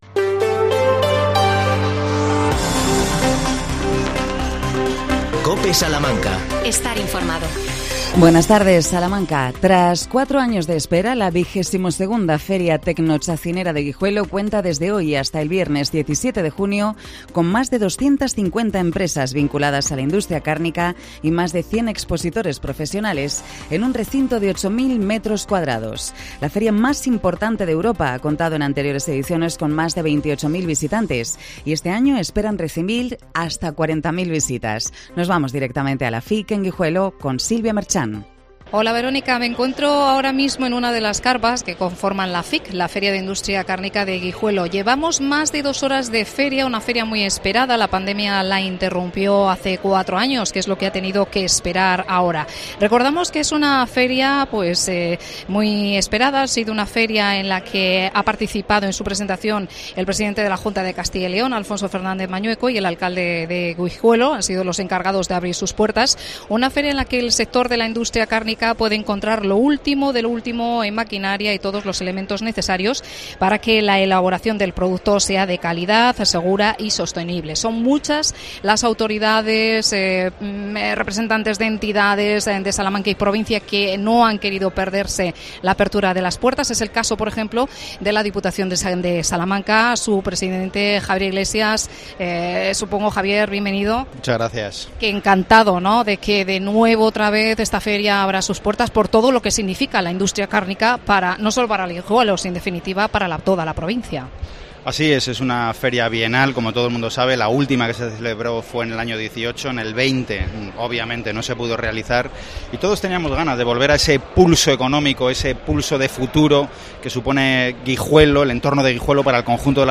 Javier Iglesias, presidente de la Diputación nos habla sobre FIC de Guijuelo. El personal de la Residencia de Mayores San Juan de Sahagún, reclama la contratación de personal